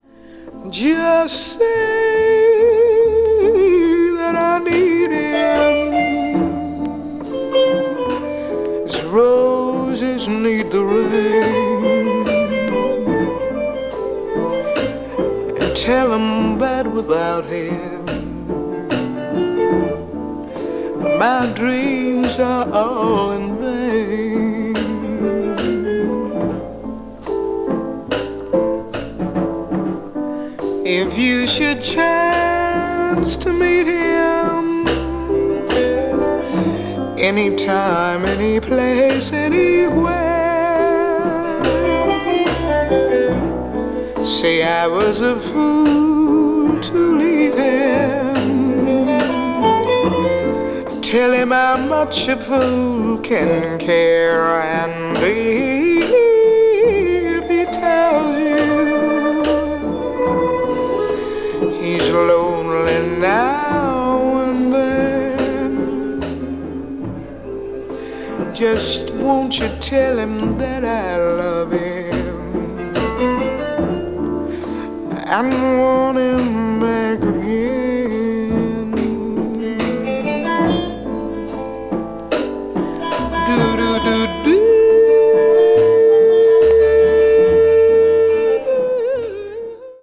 Mono
bles jazz   blues   jazz vocal   soul jazz